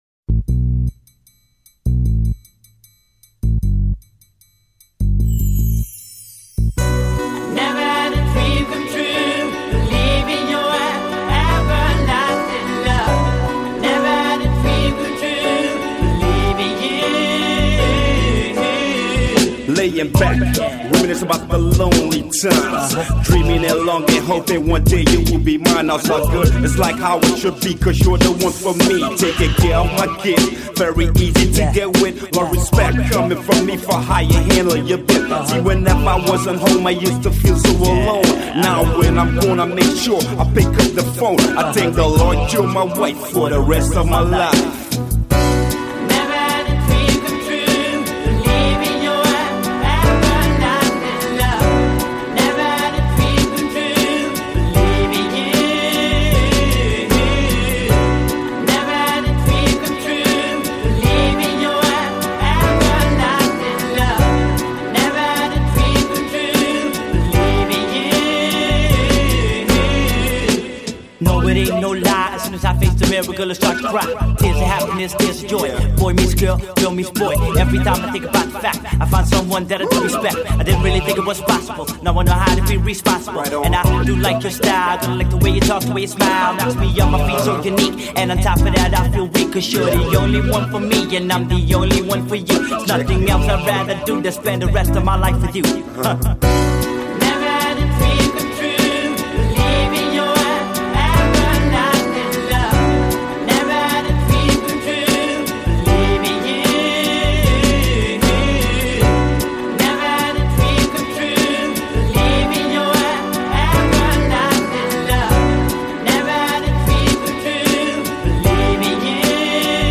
Жанр: Rap, Hip-Hop
Рэп Хип-хоп.